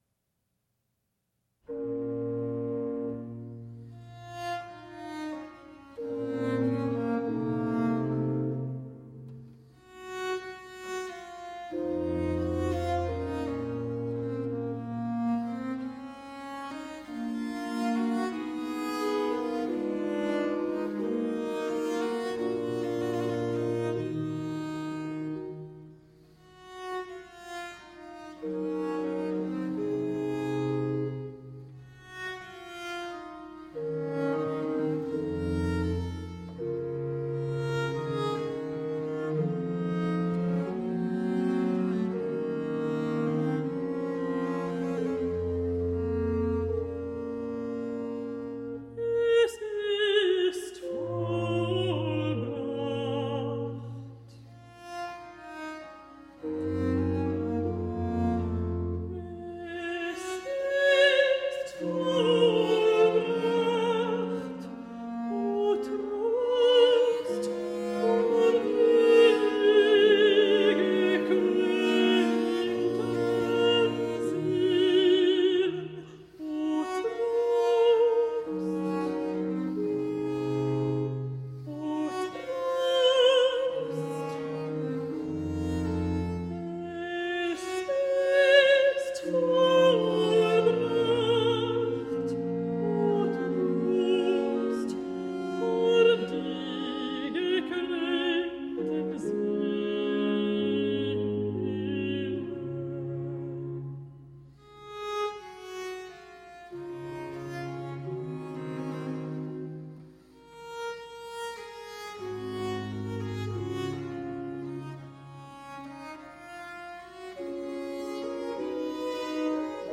Lilting renaissance & baroque vocal interpretations .
rich alto